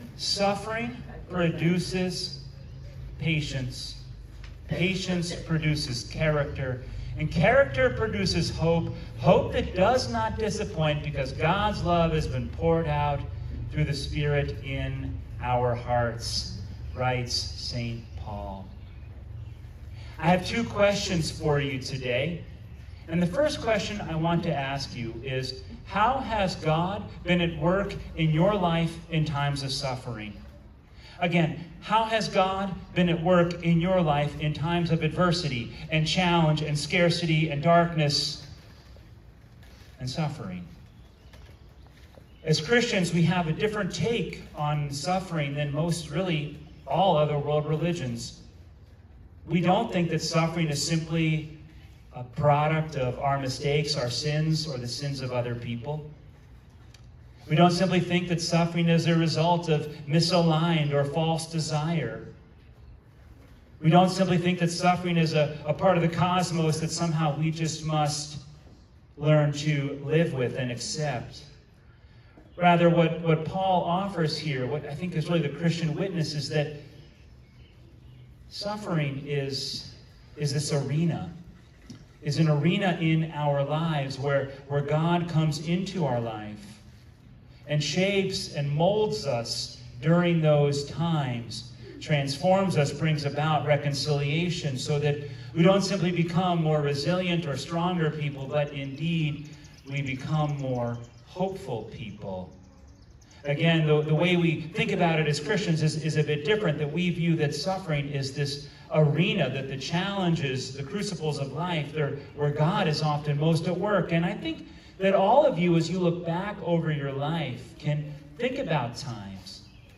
Holy Trinity Sunday (June 15 2025)